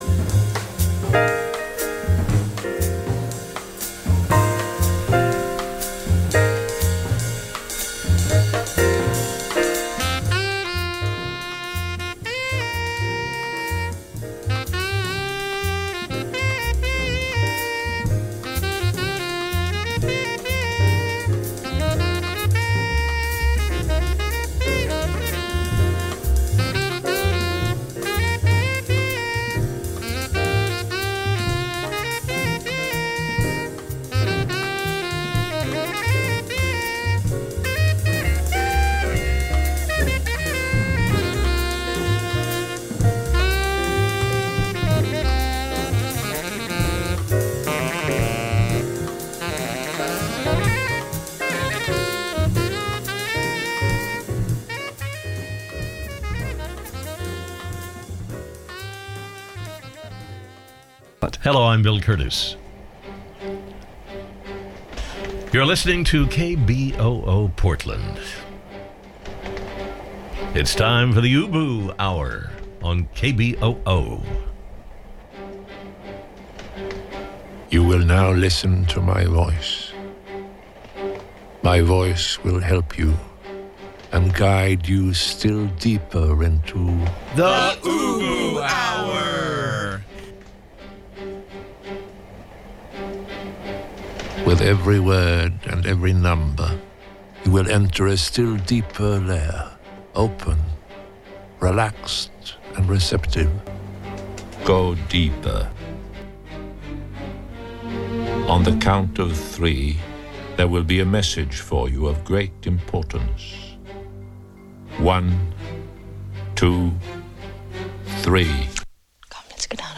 Download audio file Tonight on the UBU HOUR, the sci-fi radio thriller CRETACEOUS DARK.